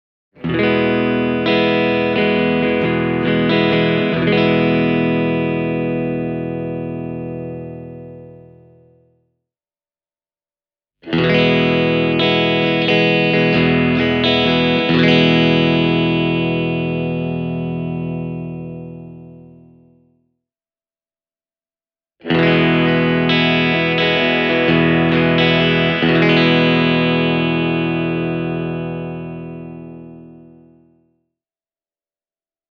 The tone of the Flaxwood MTQ doesn’t come as a surprise – this model offers an array of very tasty Tele-style sounds!
flaxwood-mtq-hybrid-e28093-clean.mp3